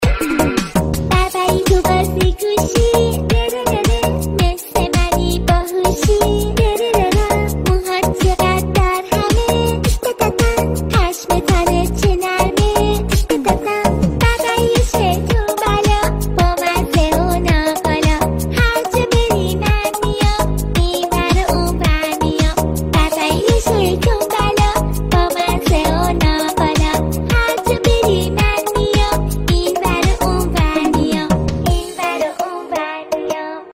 دانلود زنگ موبایل شاد برای صدای زنگ گوشی